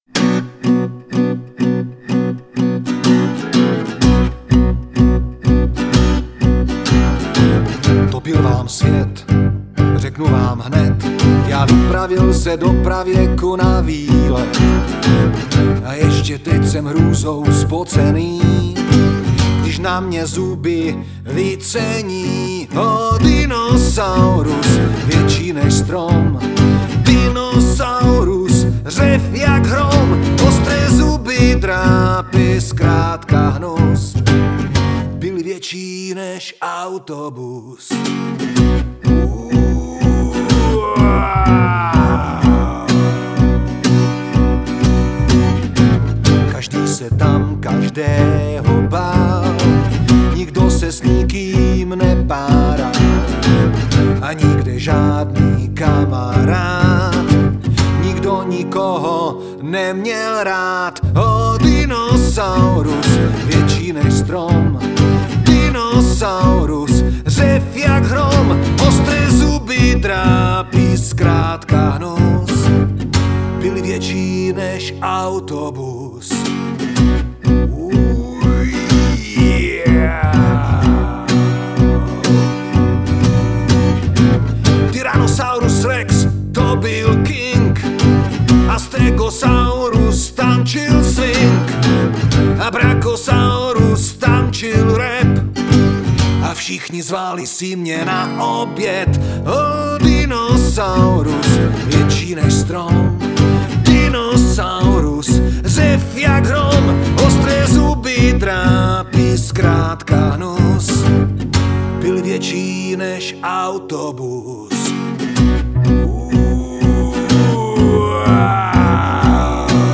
hudební show